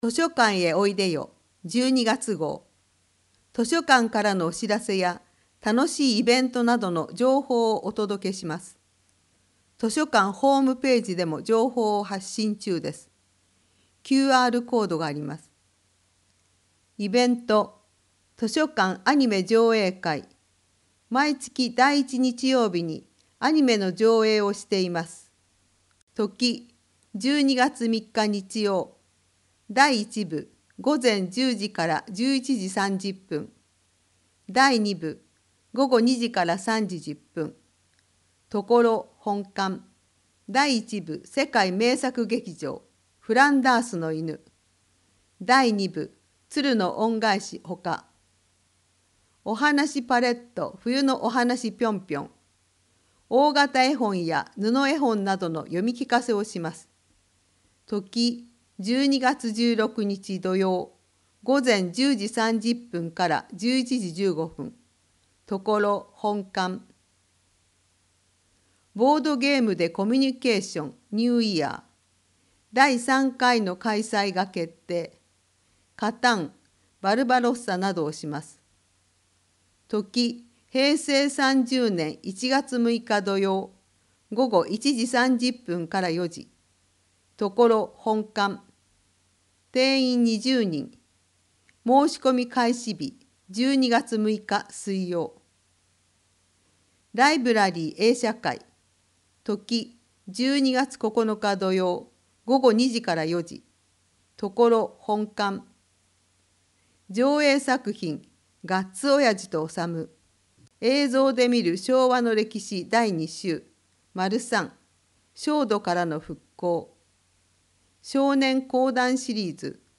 広報さんじょうを音声でお届けします